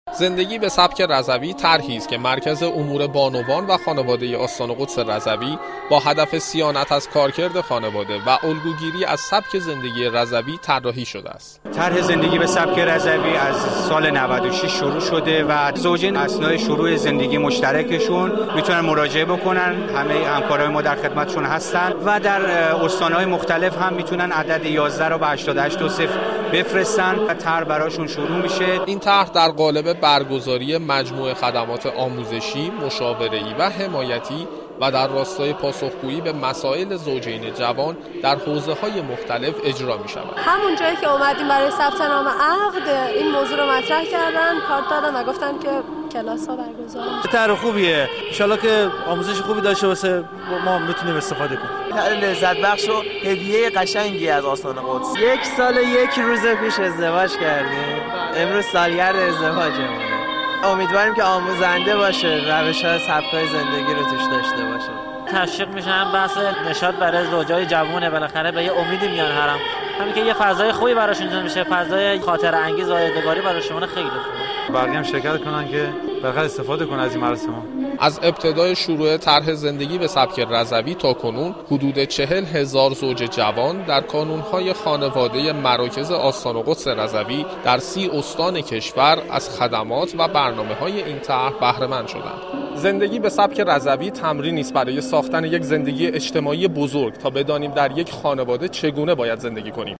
در این زمینه گزارشی بشنوید: